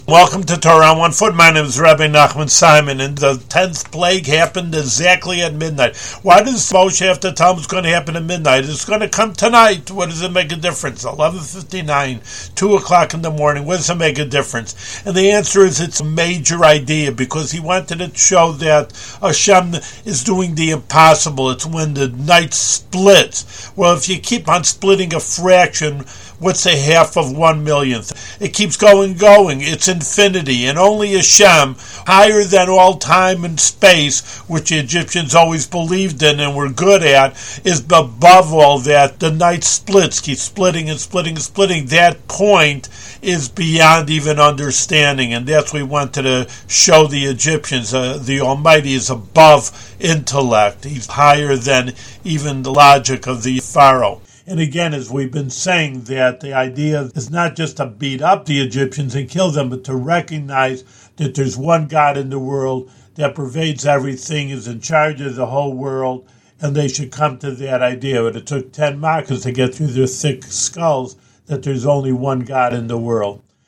Scroll down for a short audio lesson